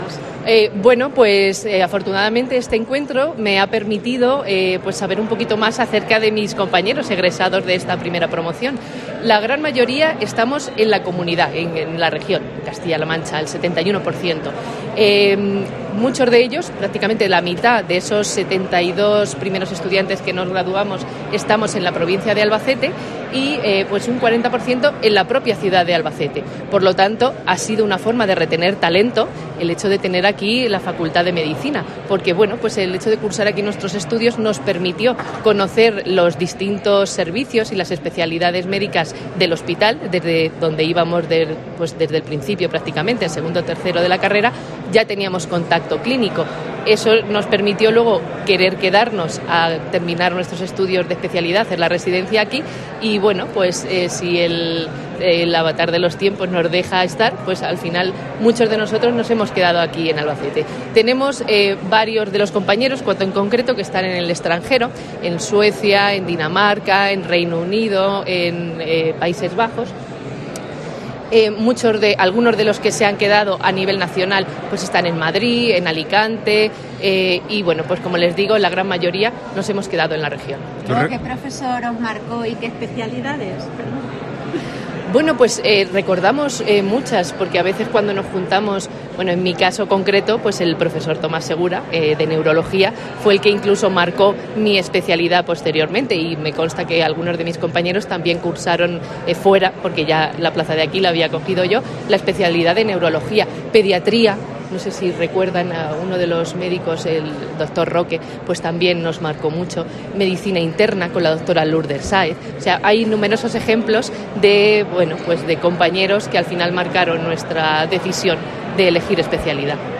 Aquí tienes su entrevista completa.